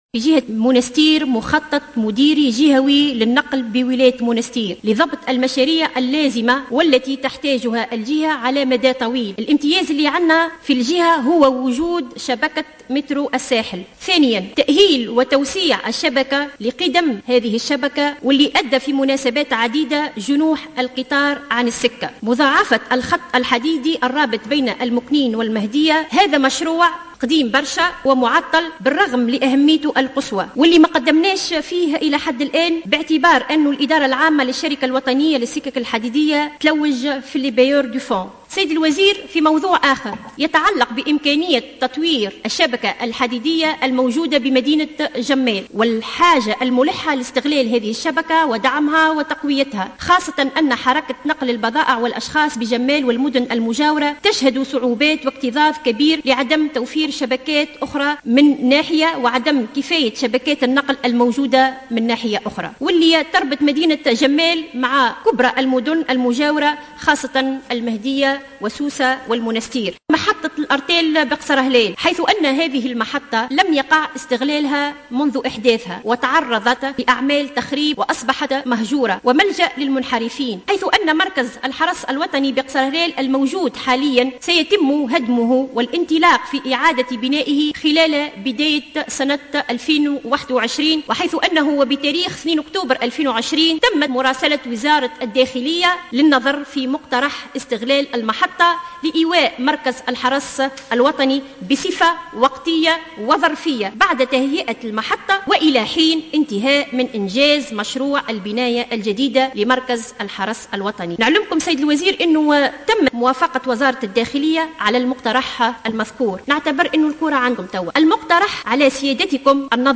أكدّت النائب لمياء جعيدان خلال الجلسة العامة المُخصصة لمناقشة مشروع ميزانية وزارة النقل واللوجيستيك لسنة 2021، أنّ قِدَم شبكة السكك الحديدية بالساحل تسبّب في جُنُوحِ القطارات في عدّة مُناسبات، داعية إلى تجديدها.